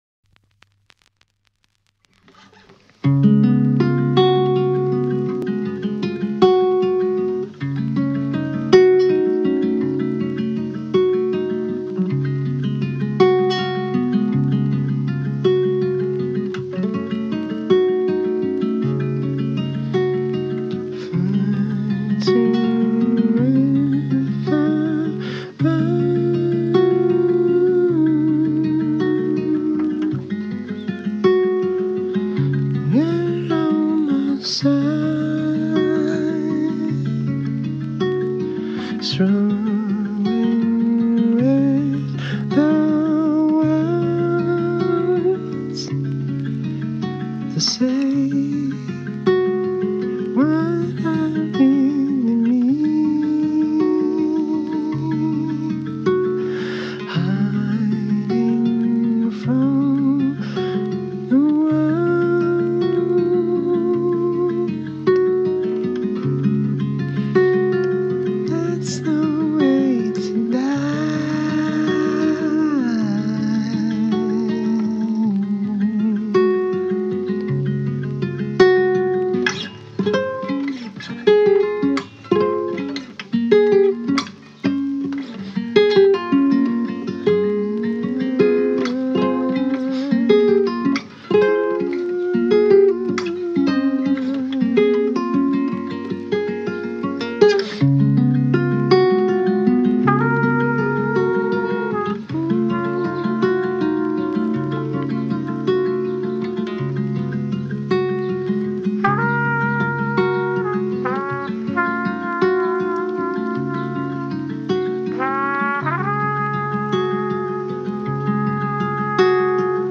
beautiful, quiet song
Tags2010s 2019 Folk indie